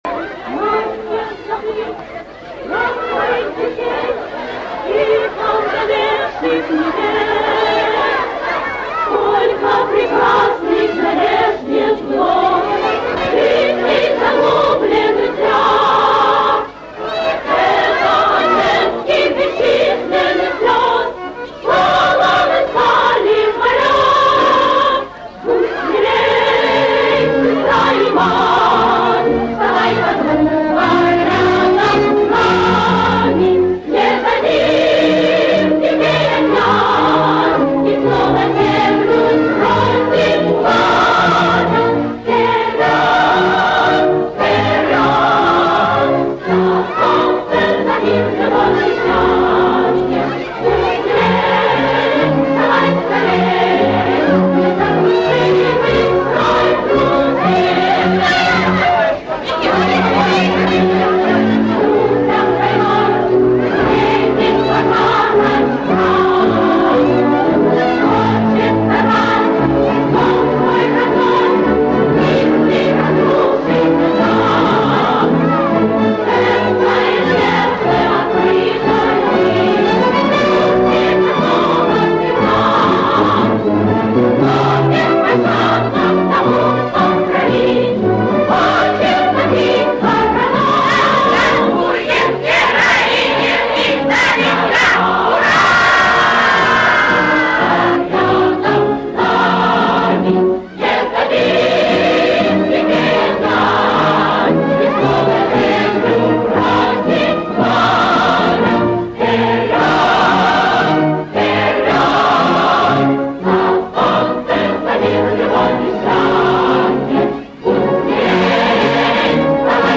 Саунд-трек
Отличная маршевая советская песня.